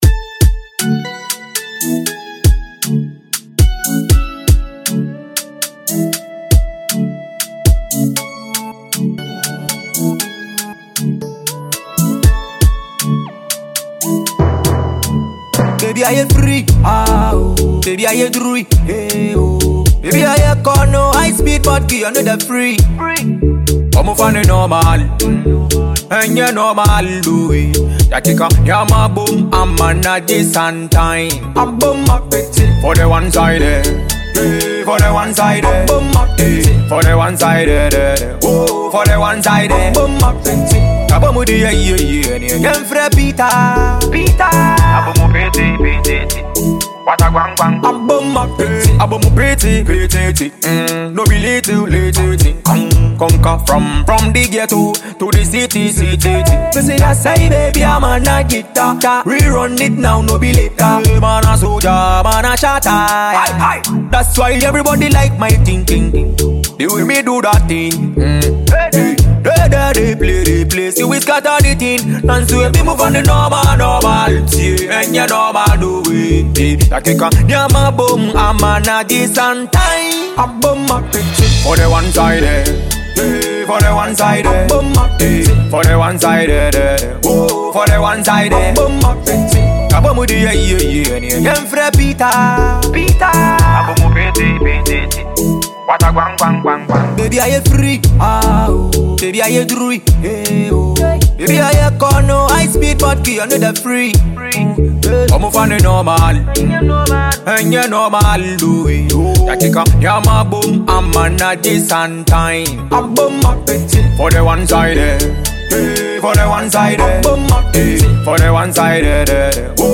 carries a playful rhythm with relatable lyrics